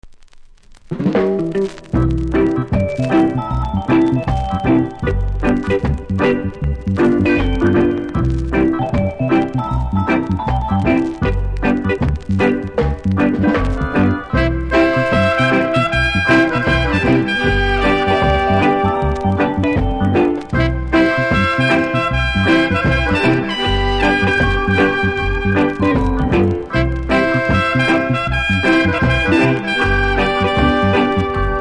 両面プレス起因のノイズありますがプレイは問題レベル。